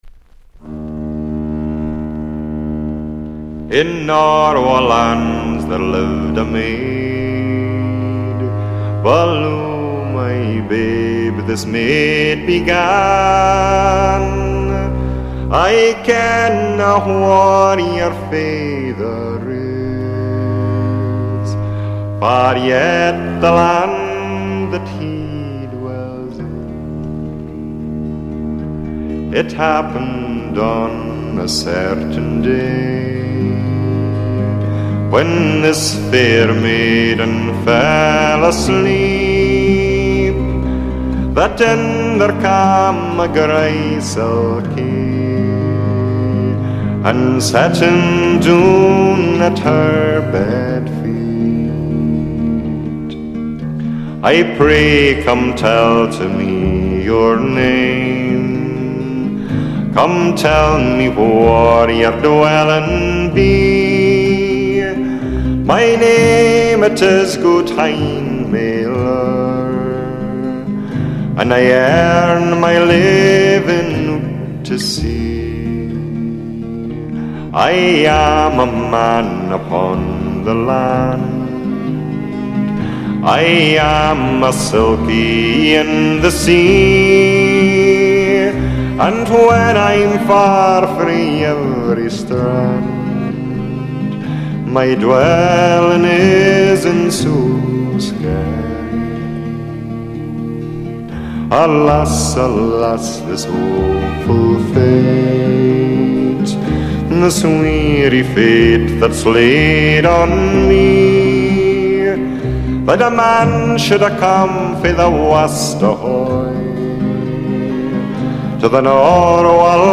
A few lines have been taken from other versions to make the plot-line more cohesive, but, essentially, it’s the ballad sung here by Archie Fisher (who calls it The Norway Maid).
Archie actually does this in Eb, but I refuse to believe any folk guitarist would voluntarily play those chords, so I’ve written it in D to be capo’ed on the first fret
The melody is essentially a slightly eerie pentatonic tune using these five notes
Rhythms start out strong but loosen up a little half-way through – not so important in a slowish ballad.